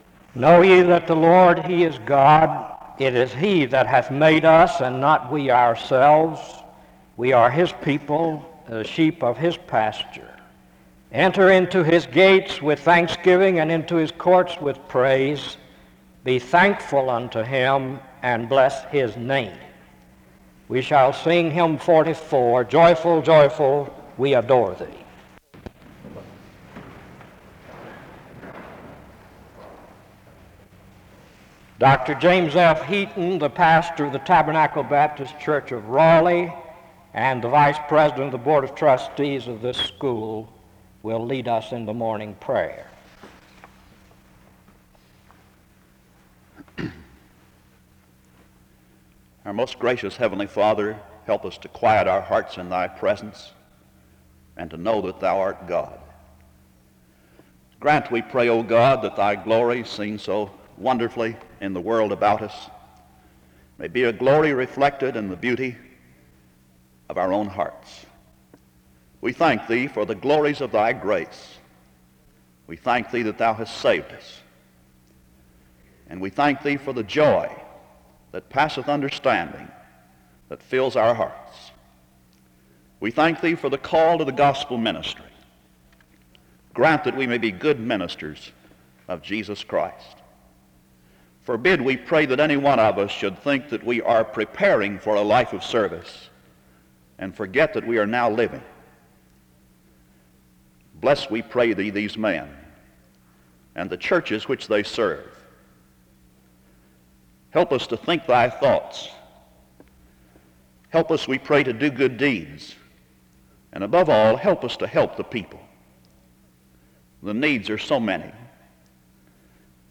The service begins with a brief introduction (0:00-0:30).
SEBTS Chapel and Special Event Recordings